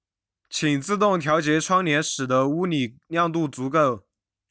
Mandarin_Voiceprint_Recognition_Speech_Data_by_Mobile_Phone